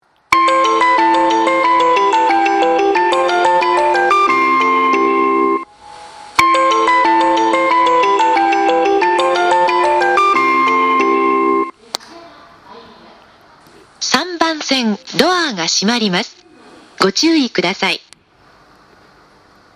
発車メロディー
2コーラスです。